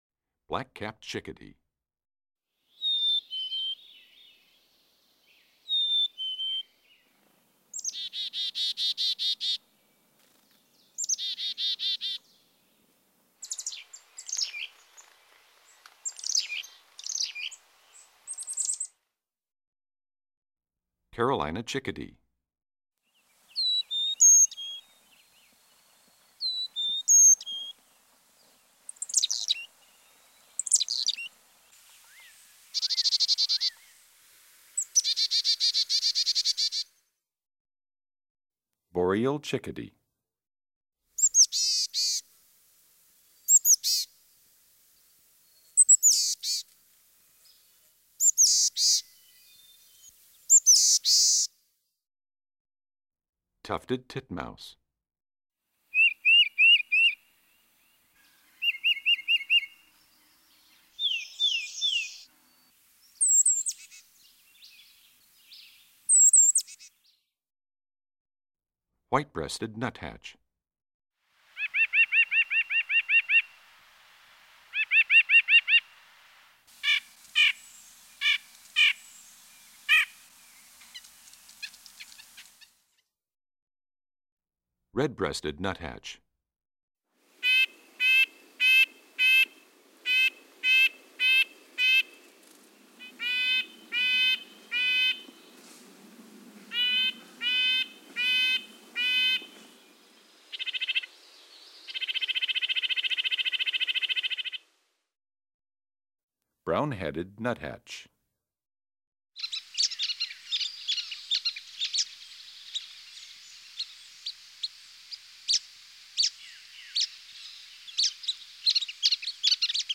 Index of /songs/Animals/Birds/Bird Songs Eastern-Central